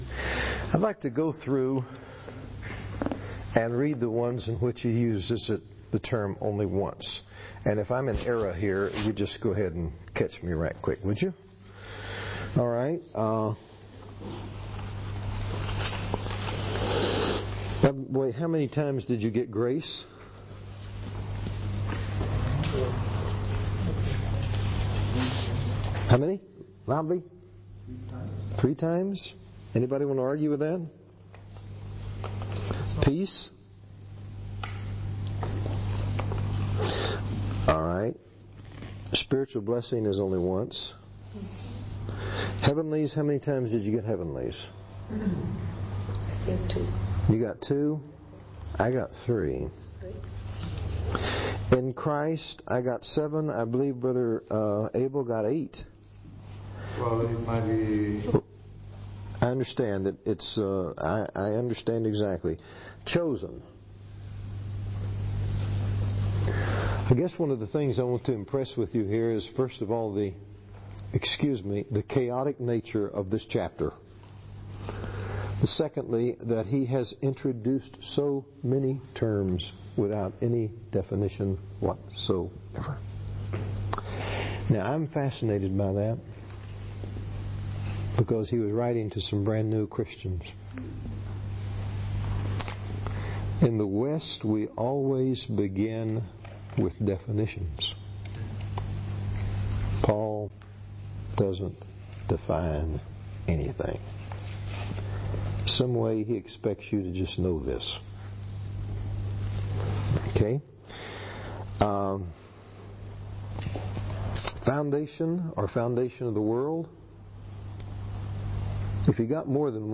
Swiss Conference Part 2 – The Church in Ephesus Part 1 Download Transcription Play Audio